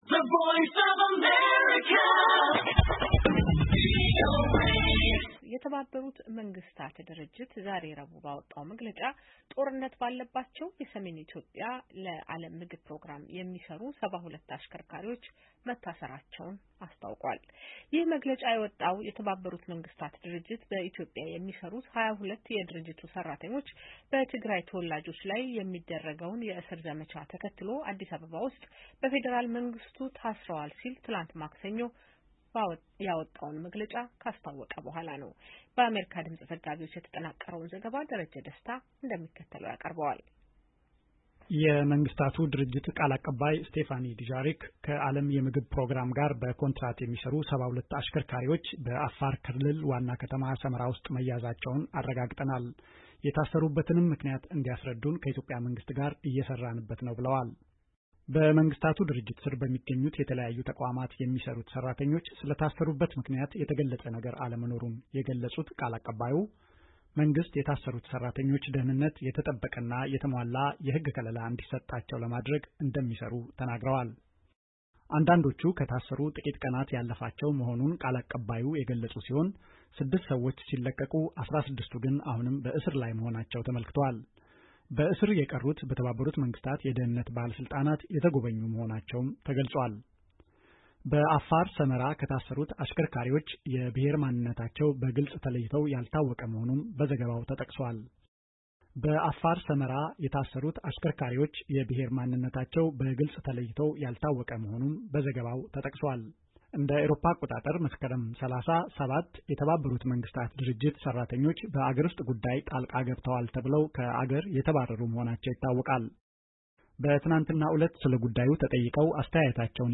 በዚህ ጉዳይ ላይ ለአሜሪካ ድምጽ ማብራሪያ የሰጡት የኢትዮጵያ መንግሥት የኮሙኒኬሽን አገልግሎት ሚኒስትር ዶ/ር ለገሰ ቱሉ፣ የሀገሪቱን ሕግ በተለይም የአስቸኳይ ጊዜ አዋጅን በመተላለፍ የታሰሩ ሰዎች ውስጥ የተመድ ሰራተኞች ሊኖሩ እንደሚችሉ ጠቁመዋል፡፡ ይሁን እንጂ “ከተመድ ሥራ ወይም ከማንነት ጋር በተያያዘ የታሰረ ሰው የለም“ ብለዋል፡፡